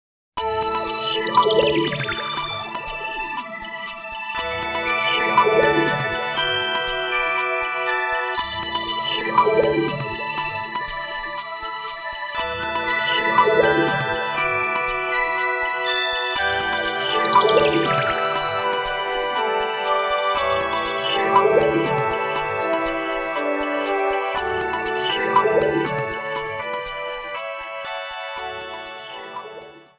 A sample of music